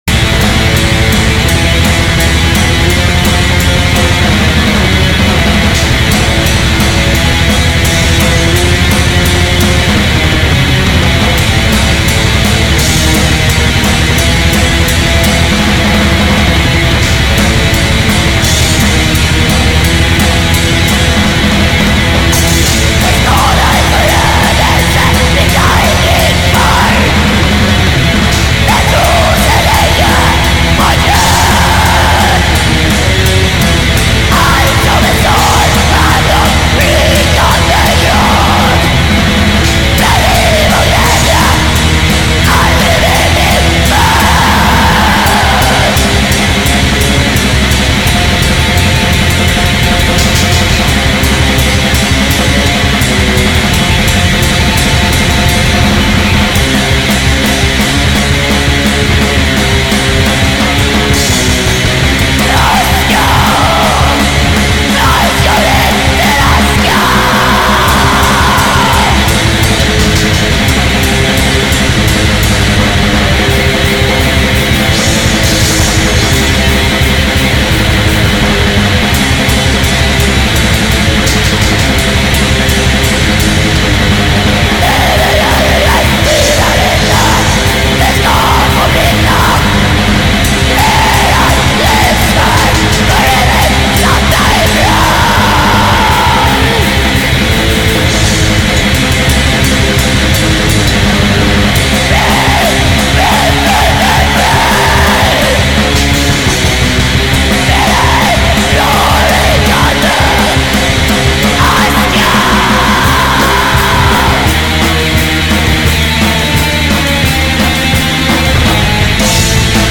Cold Swedish Black Metal.